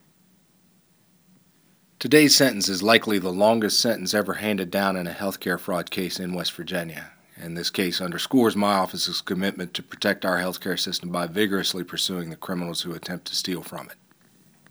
Click here to listen to an audio clip from U.S. Attorney Booth Goodwin discussing the significance of this health care fraud prosecution.